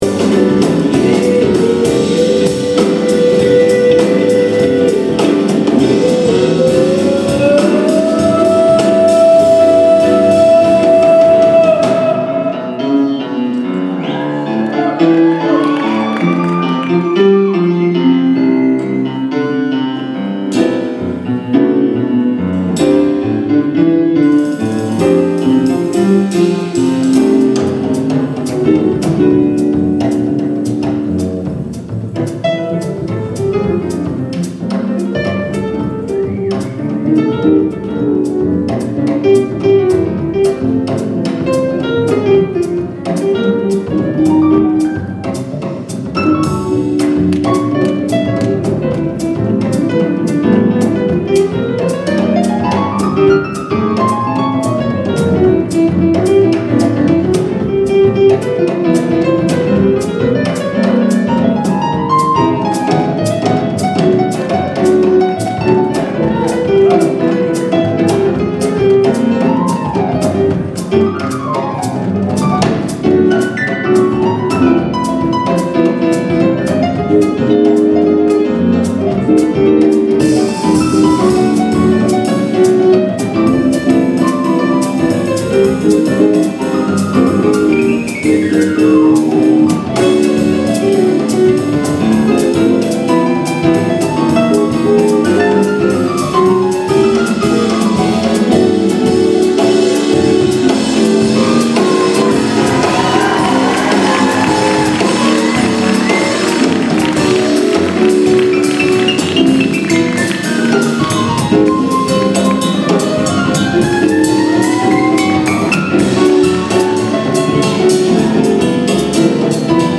some percussion/beat-boxing
Lincoln Theatre, DC, 6/2/2015